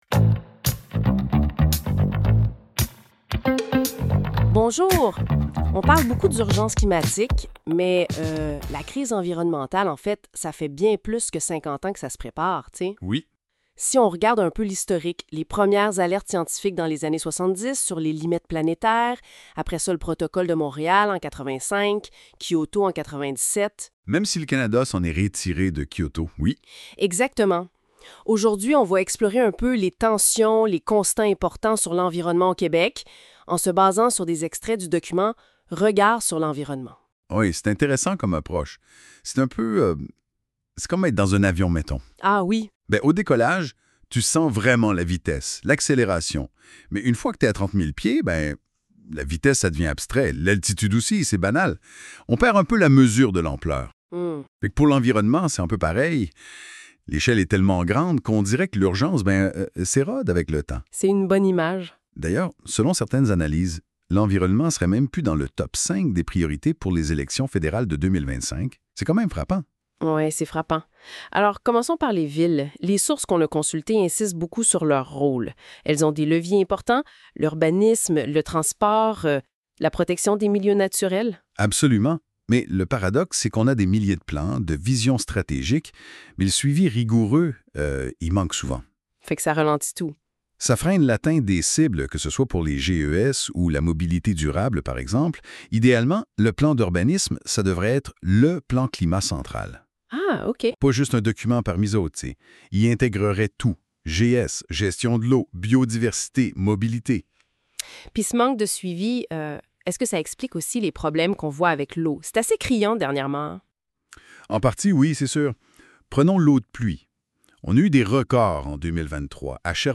Ce podcast est généré par intelligence articifielle